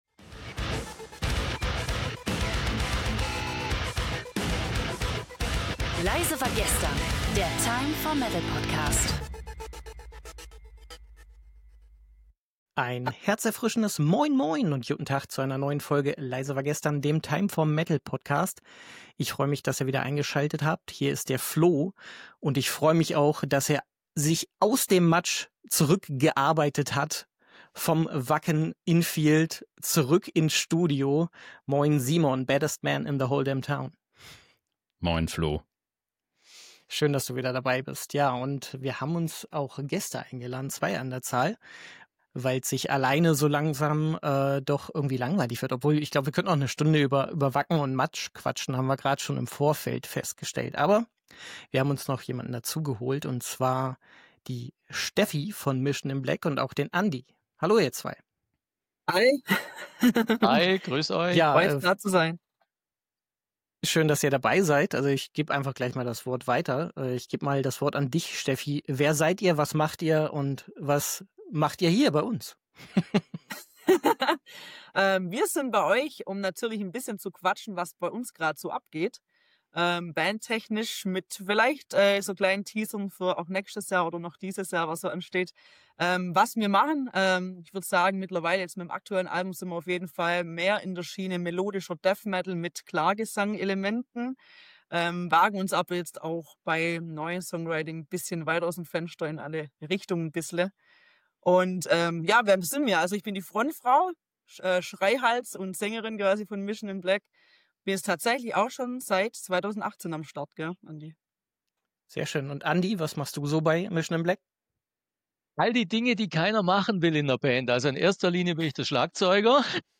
Am Ende gibt’s natürlich auch wieder eine besondere Musikauswahl fürs Outro.